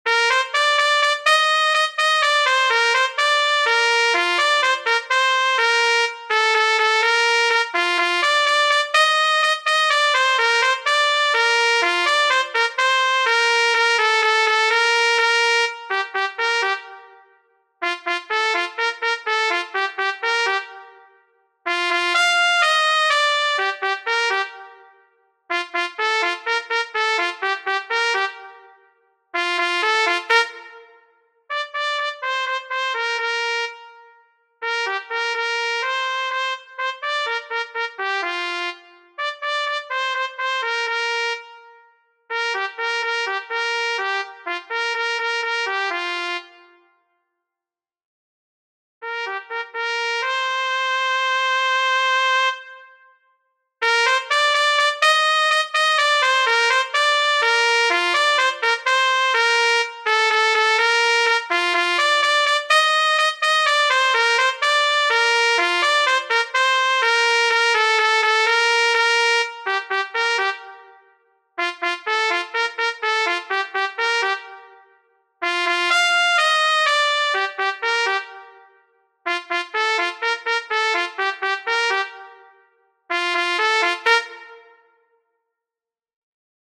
trąbka solo